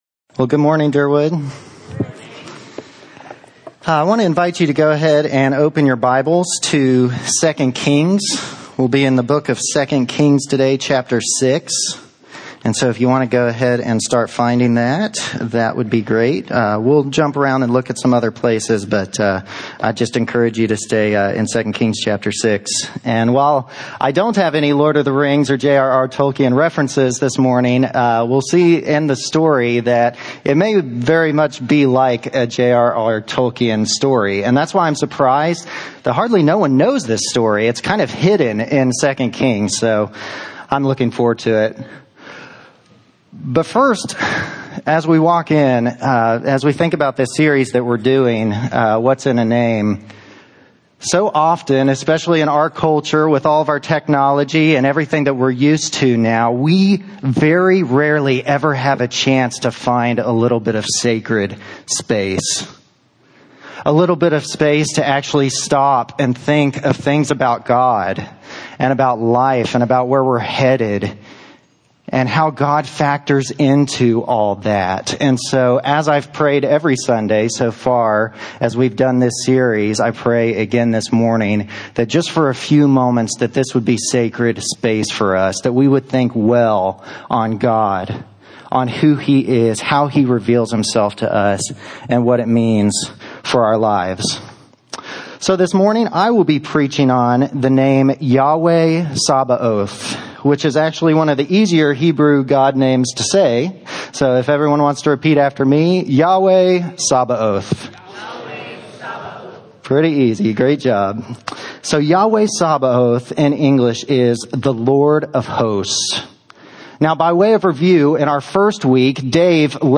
Sermons (audio) — Derwood Bible Church